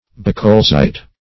Bucholzite \Buch"ol*zite\, n. [So called from Bucholz, a German